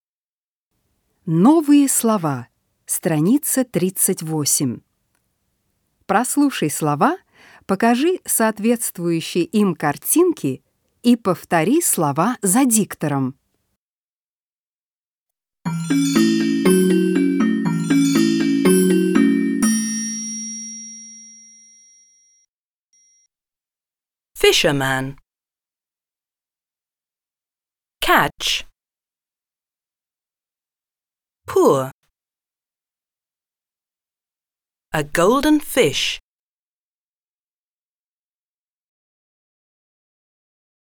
1. Прослушай слова, покажи соответствующие картинки и повтори слова за диктором.
08-Новые-слова-с.-38-.mp3